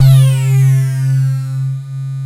ARP BAS C2-R.wav